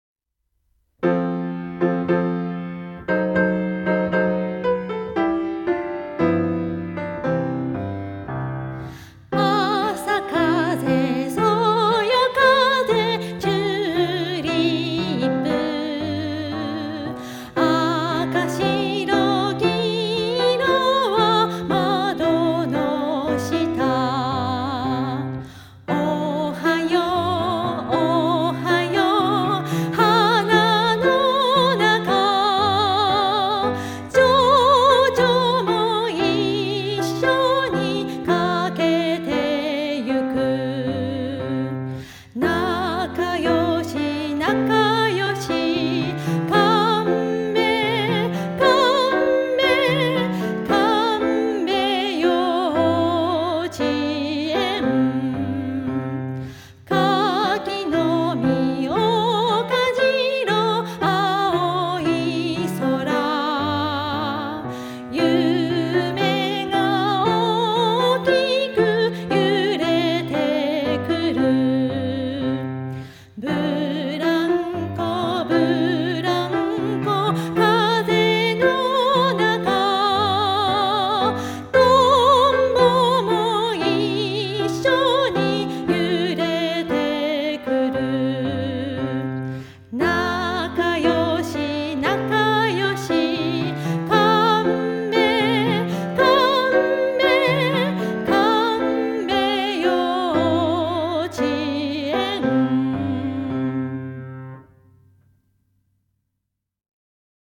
幼稚園 園歌